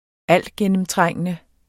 Udtale [ -ˌgεnəmˌtʁaŋˀənə ]